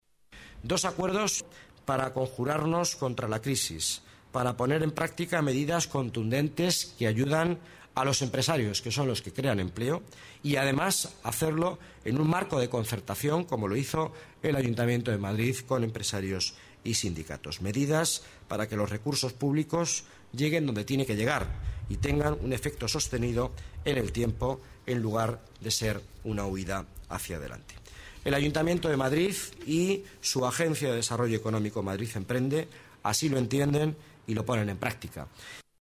Nueva ventana:Miguel Ángel Villanueva, delegado de Economía y Empleo